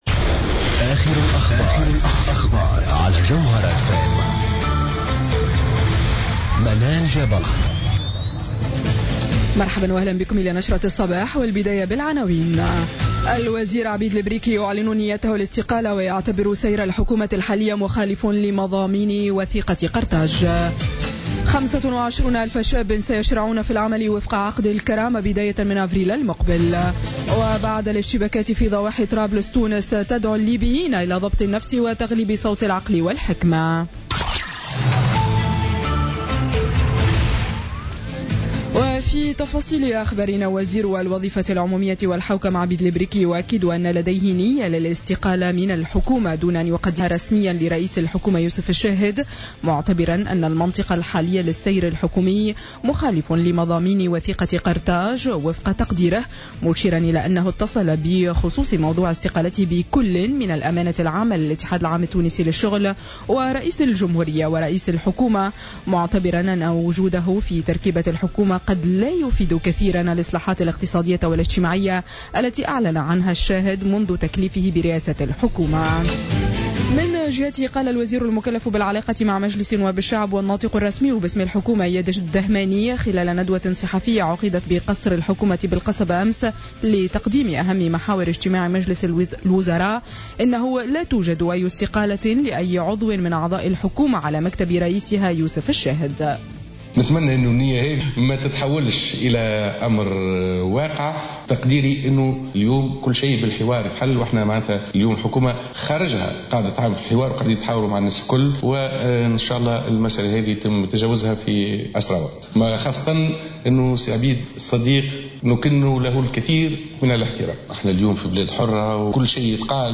نشرة أخبار السابعة صباحا ليوم السبت 25 فيفري 2017